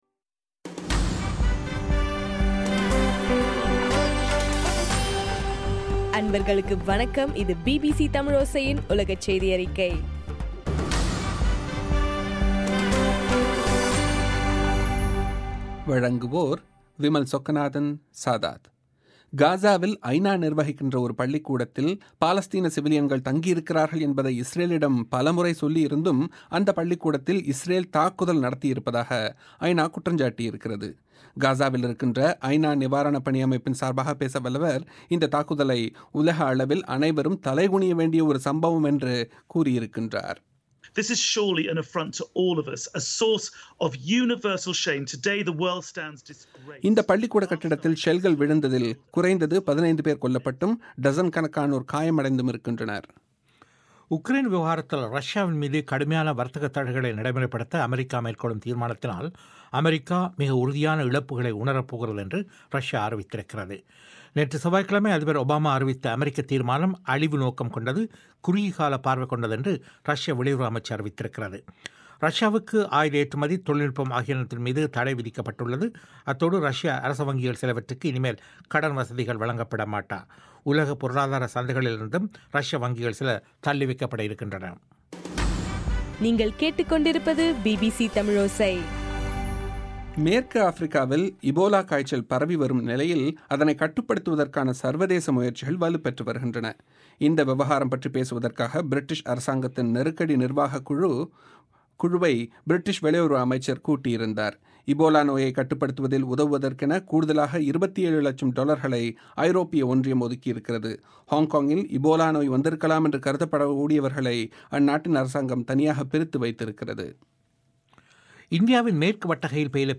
இன்றைய ( ஜூலை 30) பிபிசி தமிழோசை உலகச் செய்தியறிக்கை